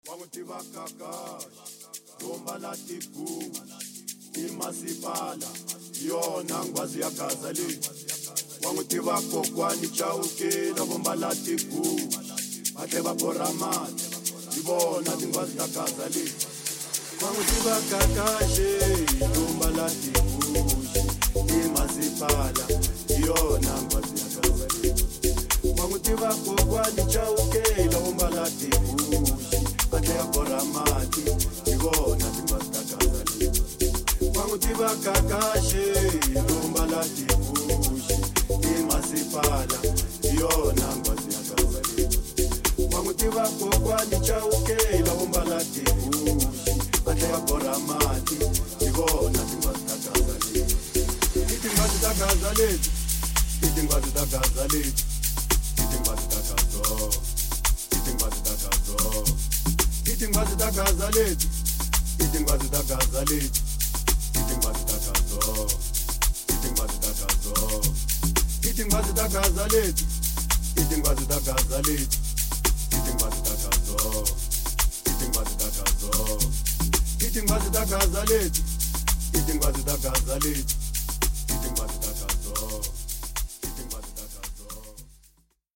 01:25 Genre : Amapiano Size